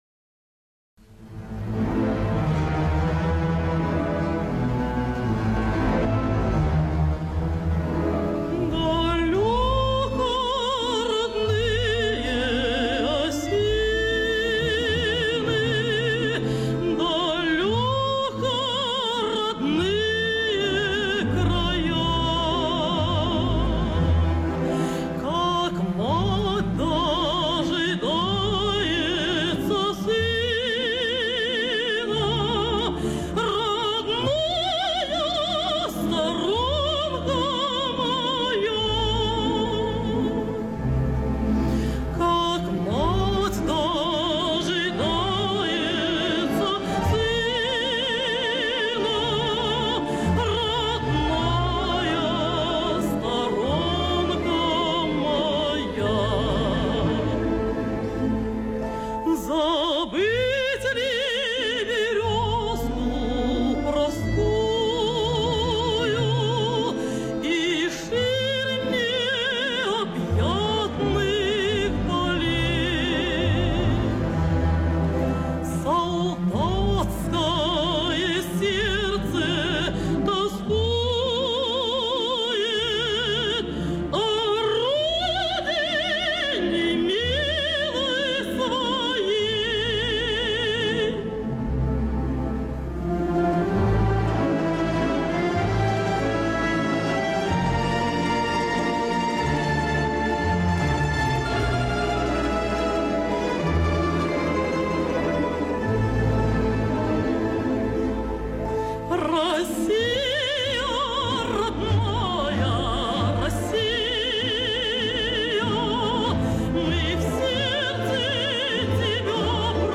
Одна из ранних записей замечательной песни